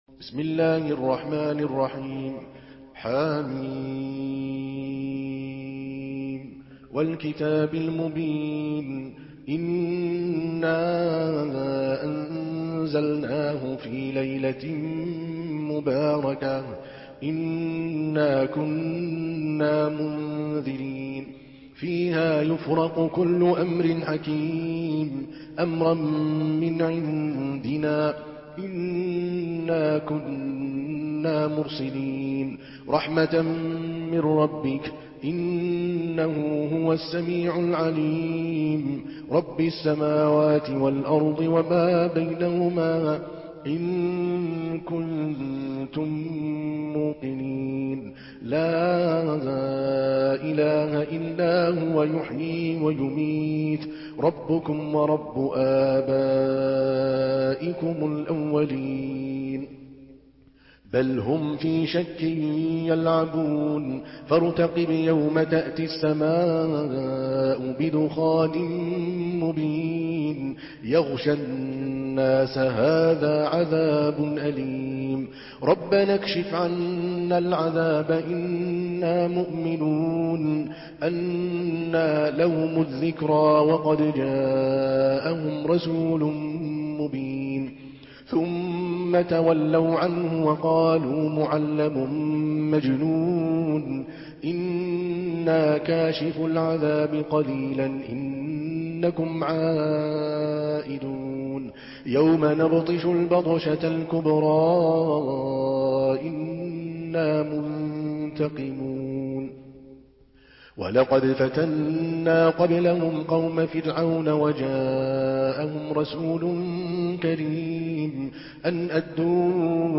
Surah الدخان MP3 in the Voice of عادل الكلباني in حفص Narration
مرتل